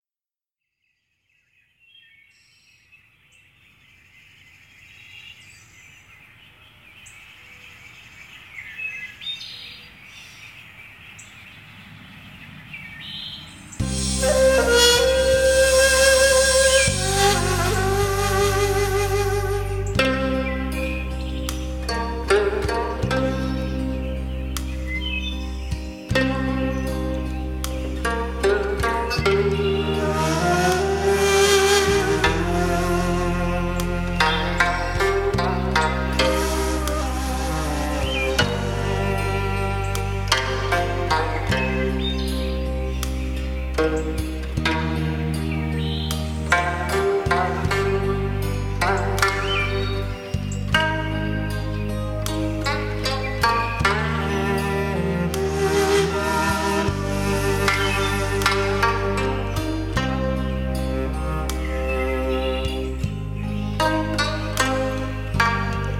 应用世界音乐风格的编曲，加上韩国国
了以韩乐器乐『伽倻琴、奚胡、大令、杖鼓、牙筝、
ocraina....』，还包括中国二胡、
以及特殊的乐器伽倻琴和Ocarina等，